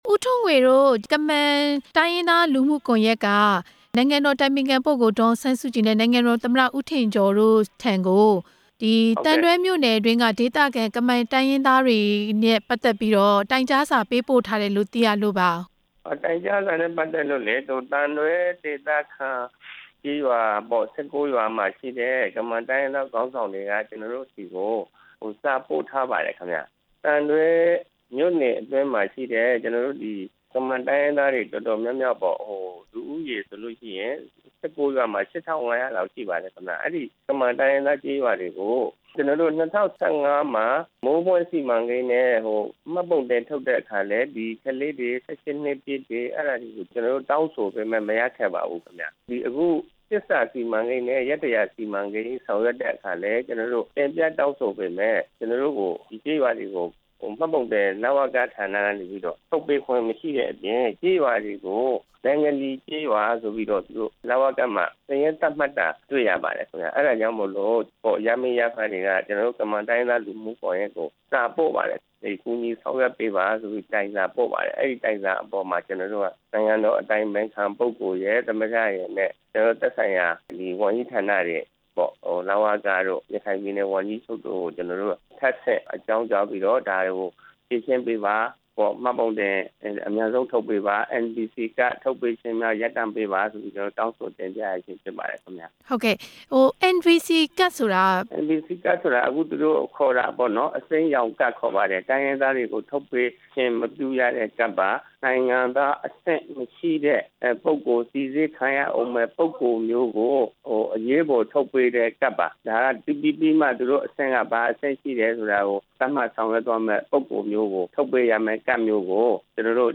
ကမန်တိုင်းရင်းသားတွေနဲ့ နိုင်ငံသား စိစစ်ရေးကတ်ပြားအရေး မေးမြန်းချက်